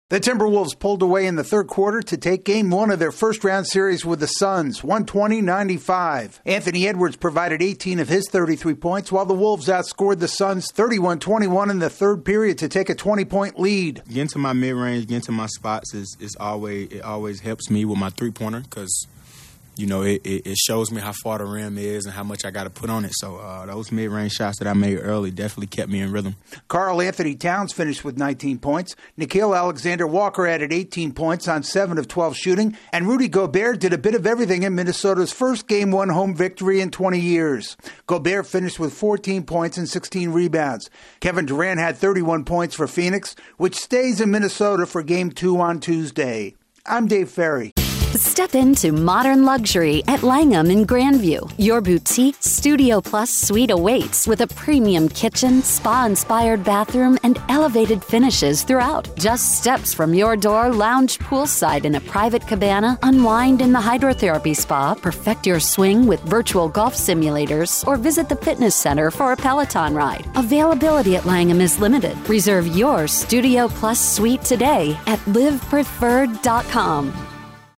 The Timberwolves strike first in their opening-round series. AP correspondent